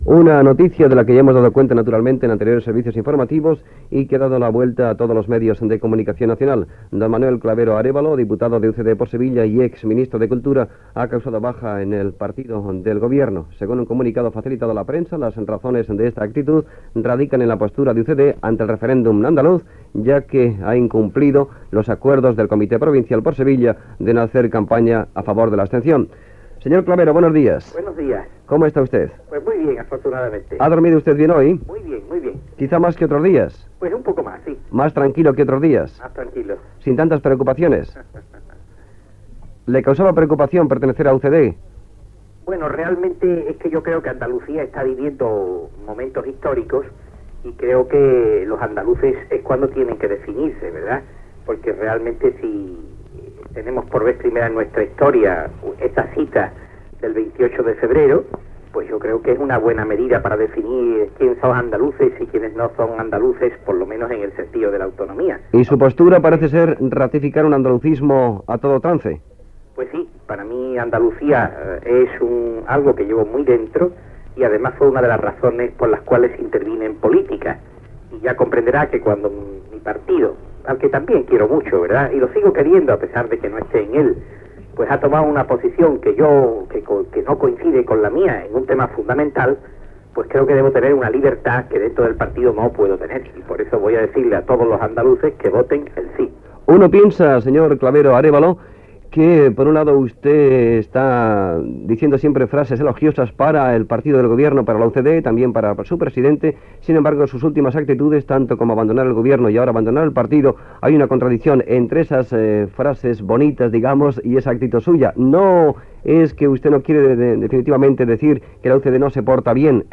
Entrevista a l'ex ministre de cultura Manuel Clavero Arévalo, que deixava de ser militant del partit Unión de Centro Democrático (UCD)